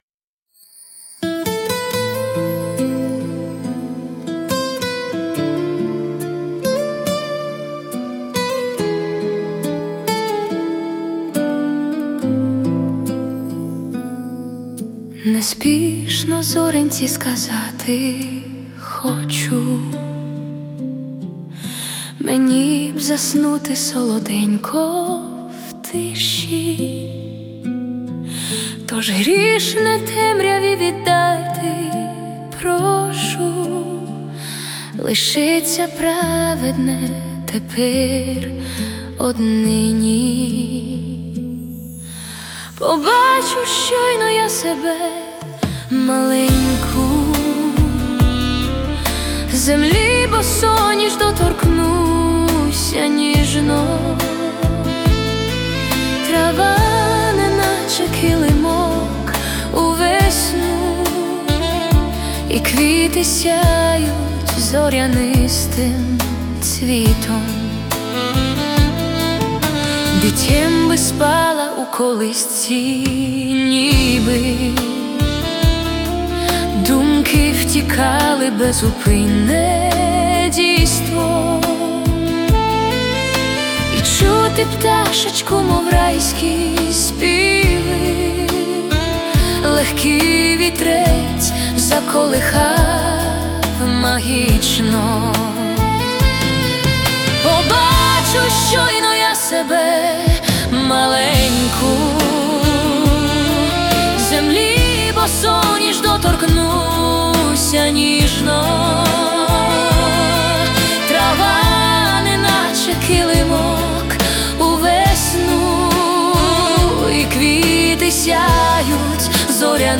Музична композиція створена за допомогою SUNO AI
Гарно та ніжно.
Ніжно, чудово, музично 16 16 16 give_rose
39 39 Дуже мила колискова собі..! 16 16 hi